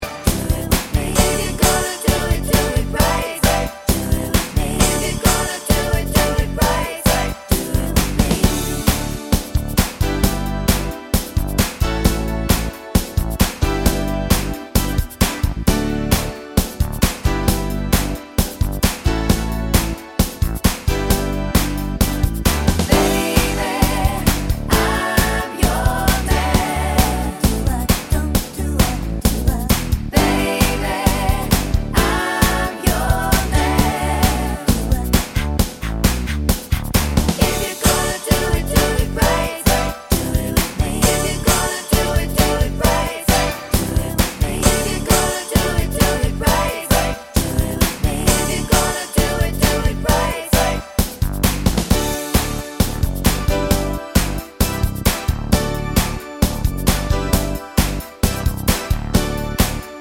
Minus Sax Pop (1980s) 3:54 Buy £1.50